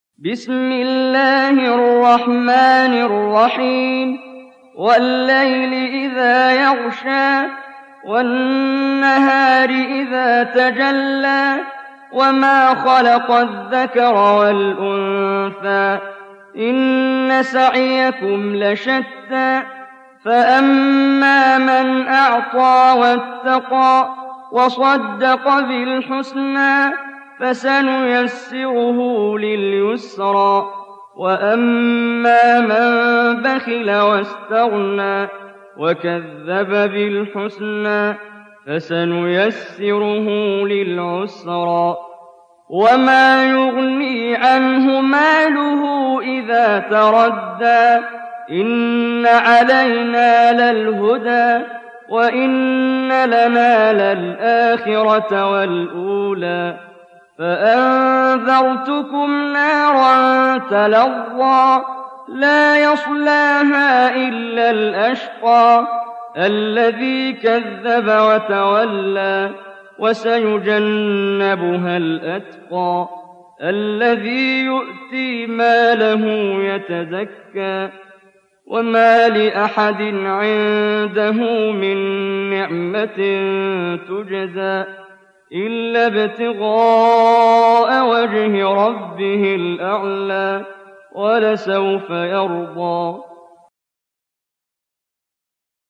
92. Surah Al-Lail سورة الليل Audio Quran Tarteel Recitation
Surah Sequence تتابع السورة Download Surah حمّل السورة Reciting Murattalah Audio for 92.